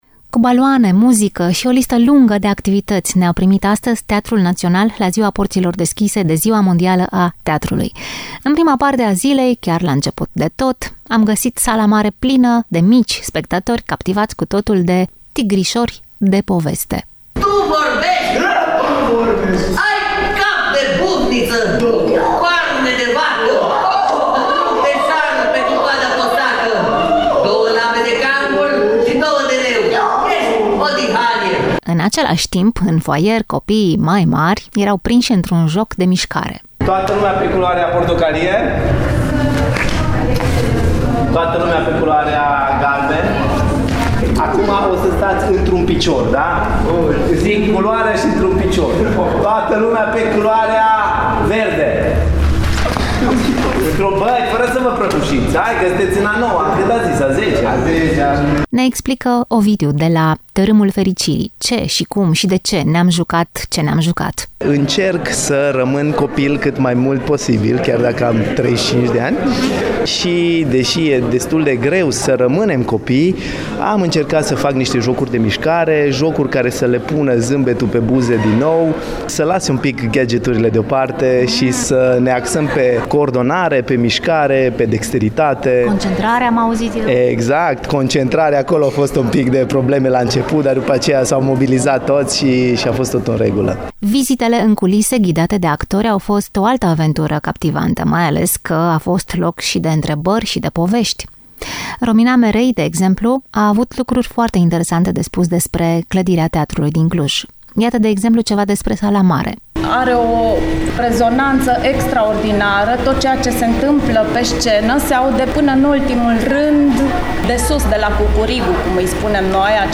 Fragmente din sărbătoarea teatrului la Teatrul Național ”Lucian Blaga” din Cluj-Napoca